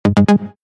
Synth.mp3